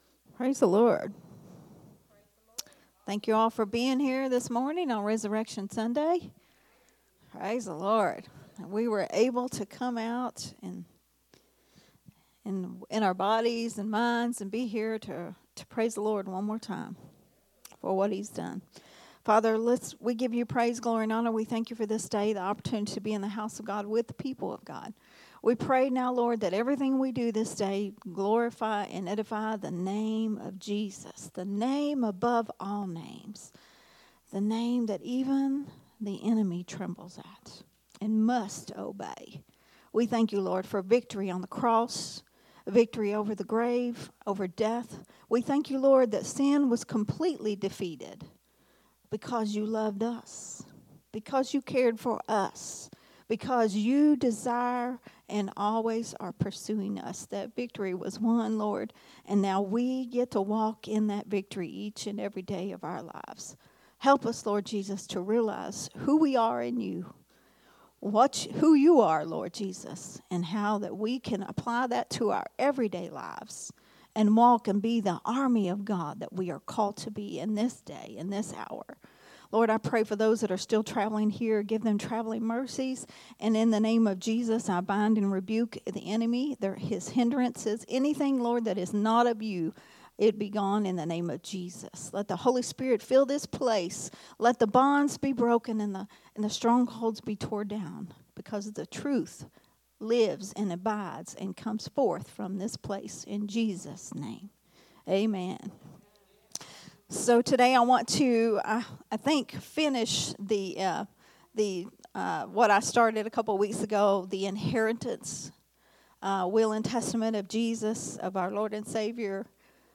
recorded at Unity Worship Center on March 31, 2024.